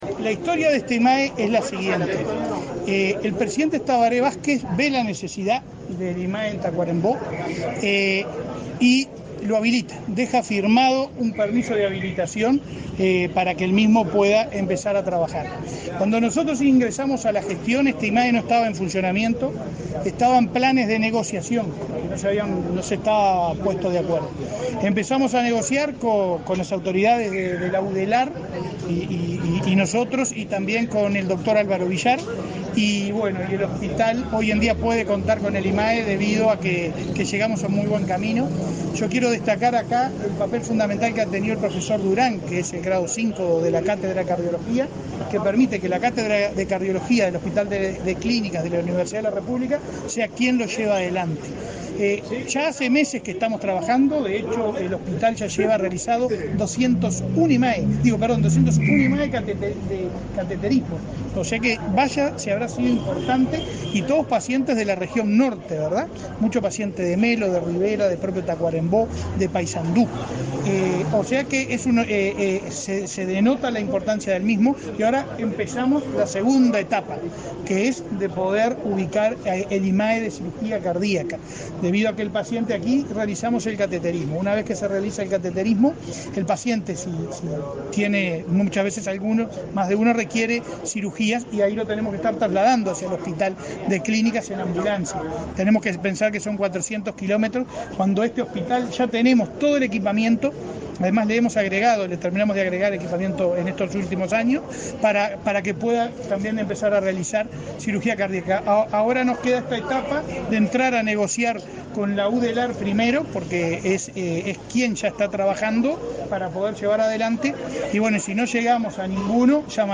Declaraciones del presidente de ASSE, Leonardo Cipriani
Declaraciones del presidente de ASSE, Leonardo Cipriani 19/05/2023 Compartir Facebook X Copiar enlace WhatsApp LinkedIn Tras participar de la inauguración del Instituto de Medicina Altamente Especializada Cardiológica en el hospital de Tacuarembó, este 19 de mayo, el presidente de la Administración de Servicios de Salud del Estado (ASSE), Leonardo Cipriani, realizó declaraciones a la prensa.